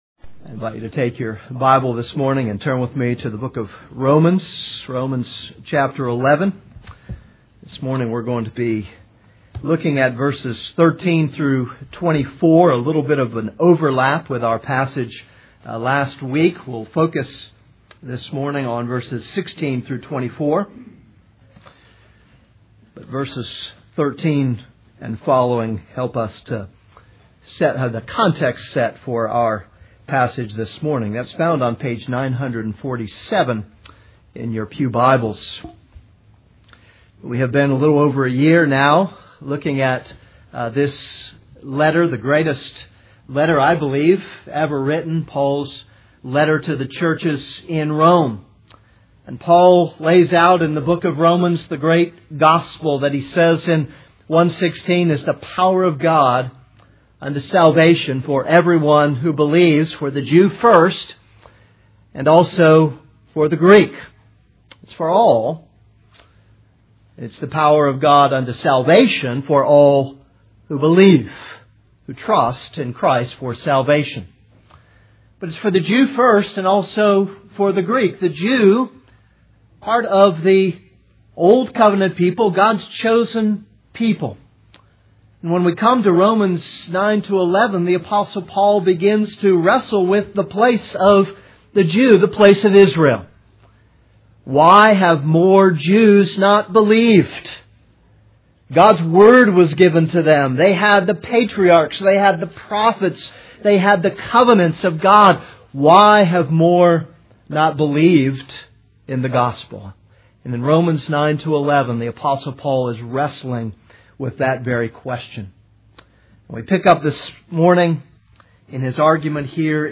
This is a sermon on Romans 11:13-24.